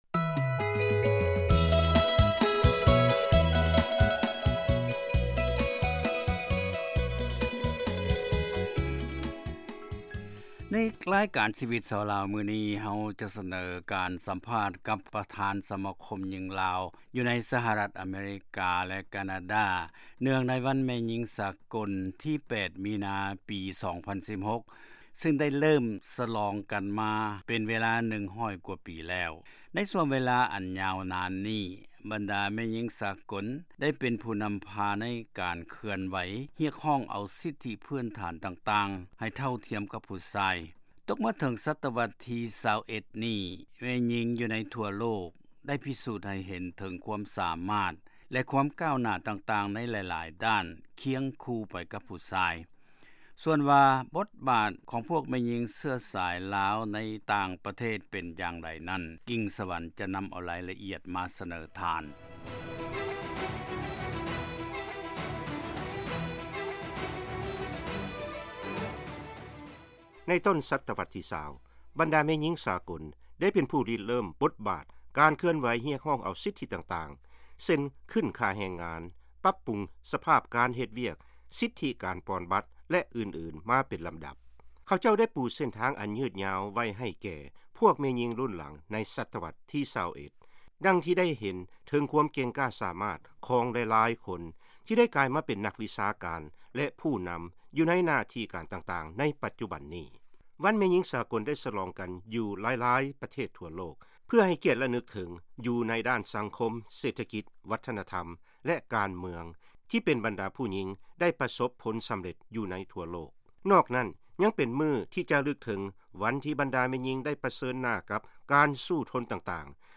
ເຊີນຟັງການສຳພາດ ບັນດາປະທານສະມາຄົມຍິງລາວ ໃນສະຫະລັດ ແລະການາດາ ເນື່ອງໃນວັນແມ່ຍິງສາກົນ